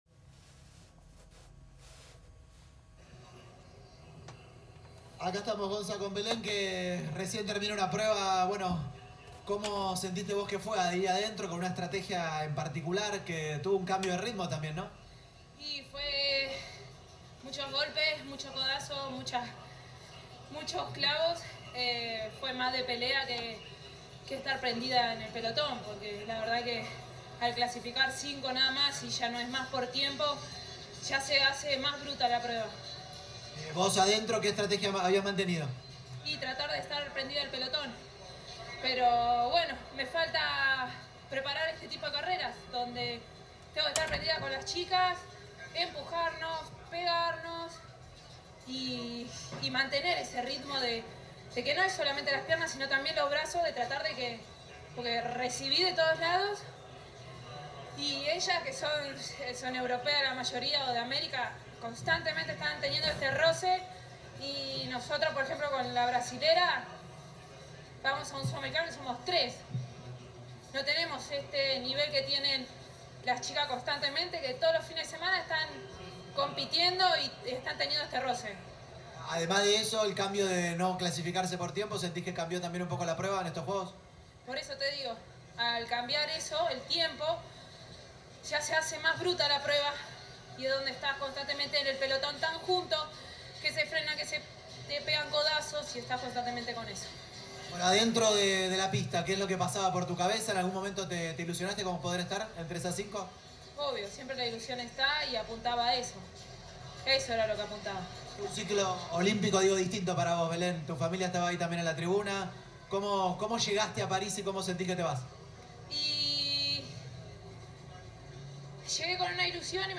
Entrevistada por TyC Sports :  “ Vine a Francia  con una expectativa muy alta; el objetivo era el de llegar a la final pero se hizo una carrera muy bruta.